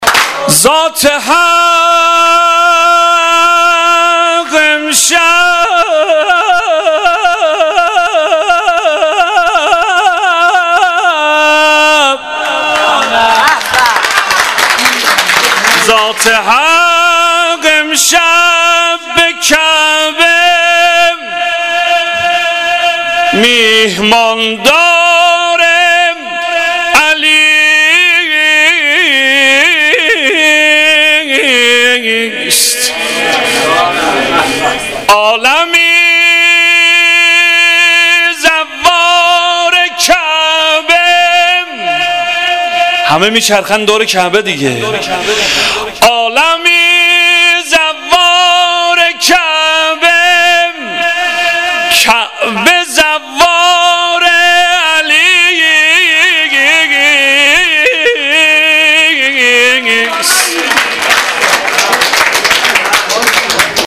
مدح _ شب میلاد امام علی (علیه السلام)